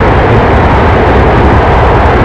Add IAE cockpit sounds
v2500-reverse.wav